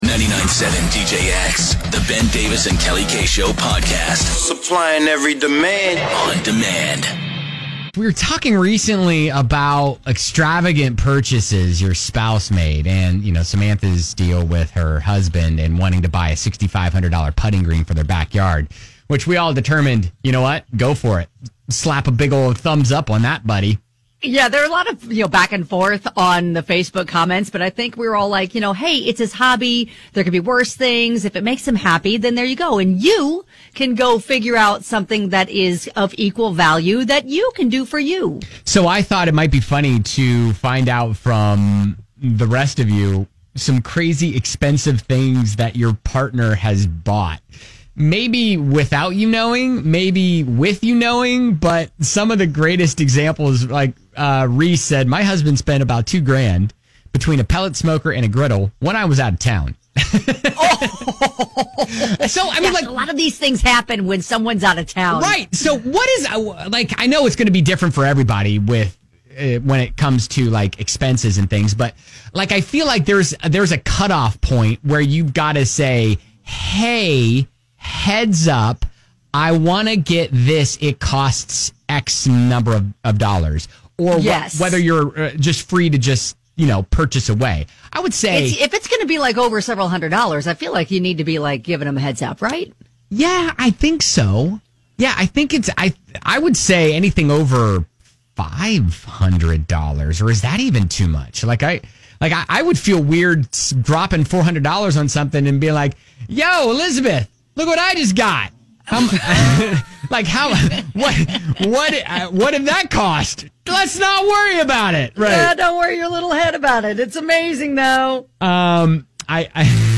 Listeners tell us the extravagant purchases their partners have made with (or without) their knowledge!